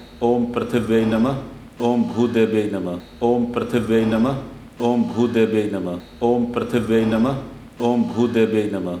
The first one is “ OM PRITHIVYAEE NAMAHA” and second one is “ OM BHOODEVIYAEE NAMAHA” – both are directed to  the Mother Earth. And in every position of Prithivi Namaskar you will have to say these mantras loudly.
Mantra.wav